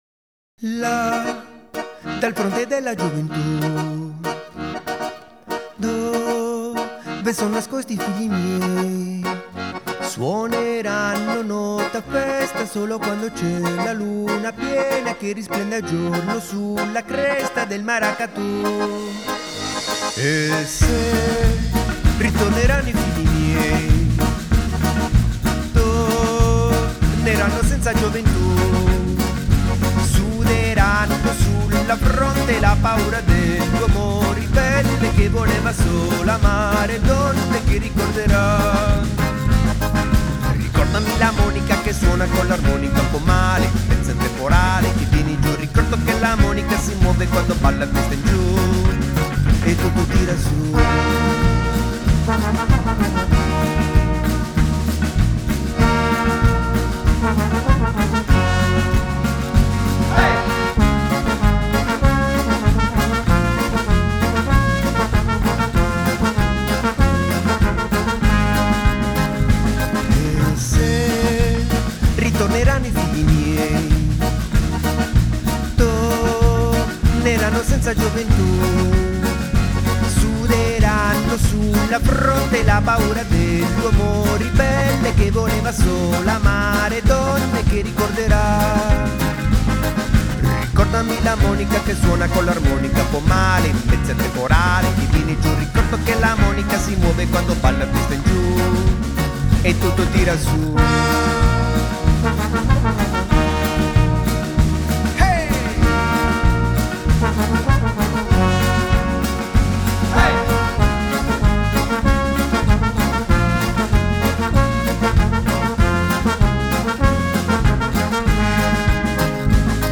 Il ritmo unico dello spaghetti samba da Bologna!